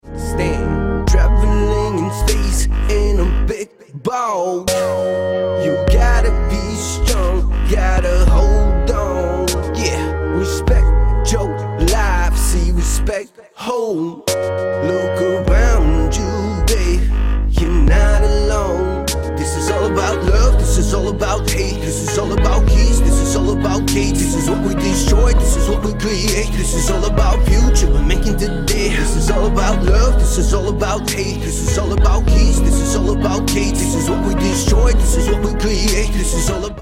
Хип-хоп
философские
качающие